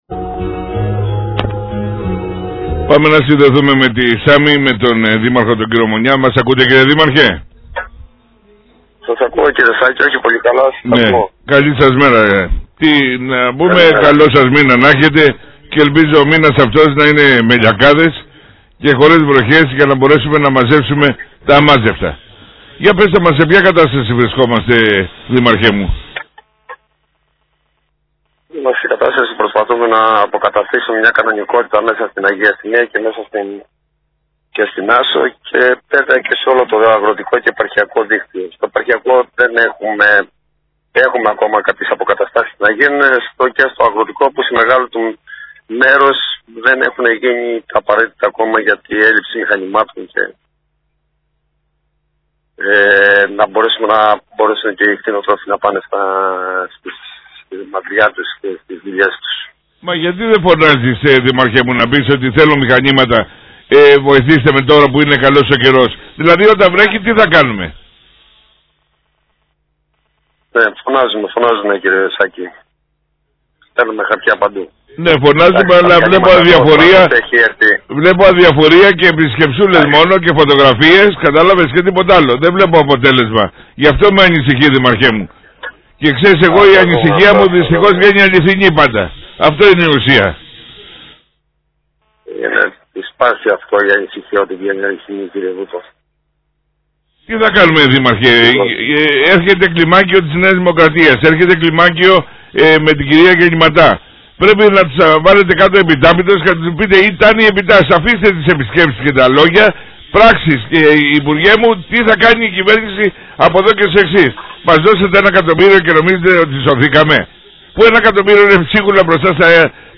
ο Δήμαρχος Μάκης Μονιάς μιλώντας στην εκπομπή